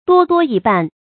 多多益辦 注音： ㄉㄨㄛ ㄉㄨㄛ ㄧˋ ㄅㄢˋ 讀音讀法： 意思解釋： 見「多多益善」。